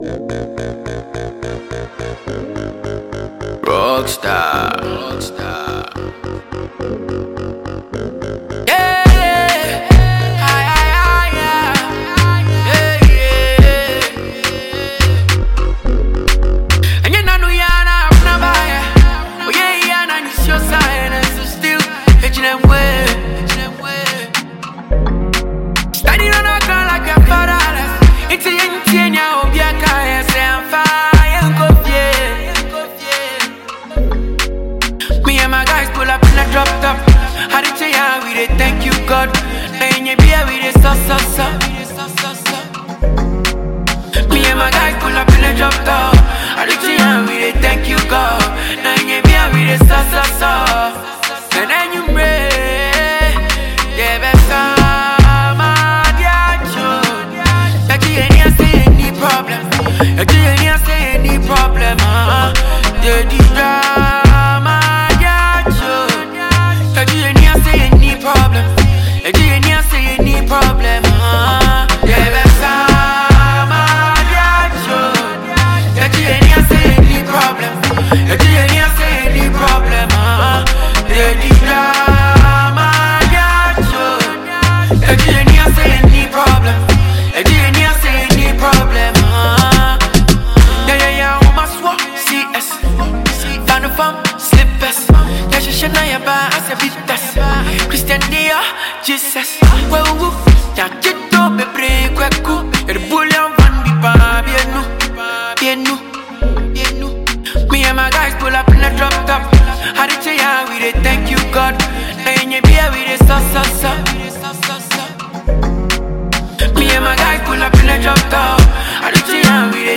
” which features captivating melodies and intriguing rhythms